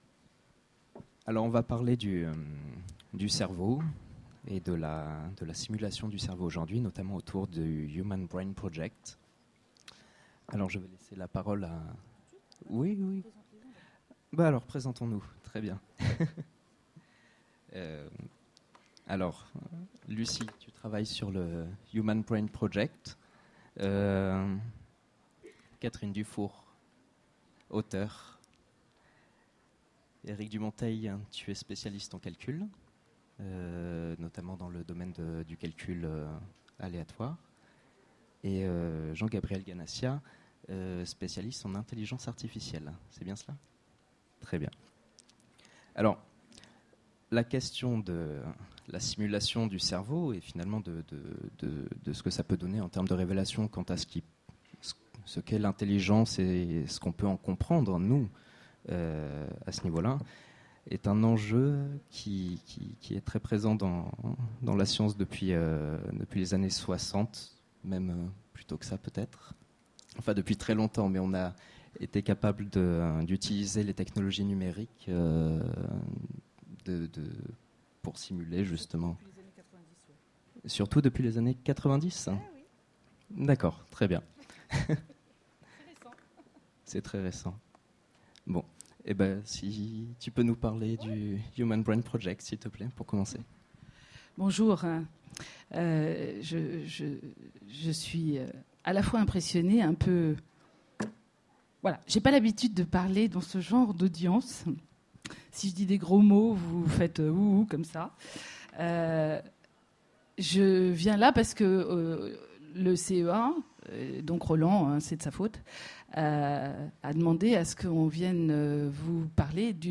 Mots-clés Intelligence artificielle Conférence Partager cet article